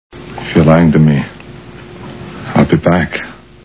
Twins Movie Sound Bites